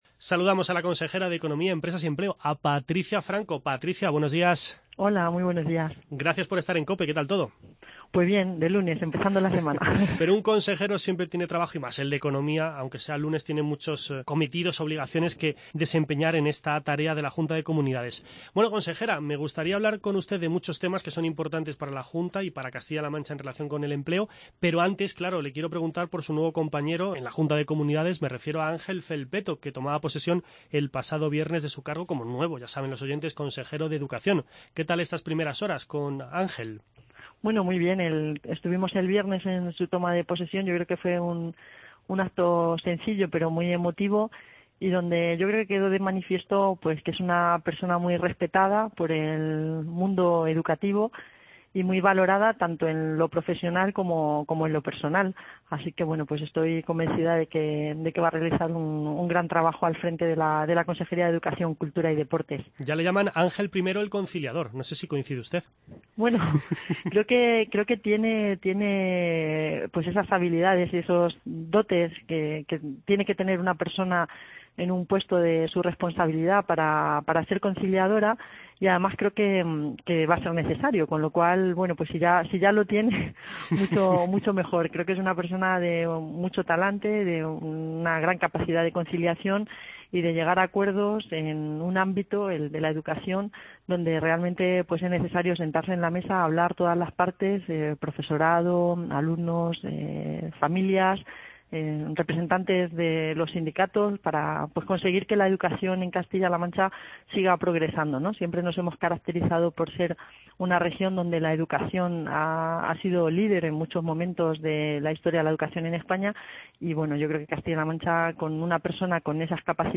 En esta jornada de lunes charlamos en COPE con la consejera de Economía, Empresas y Empleo.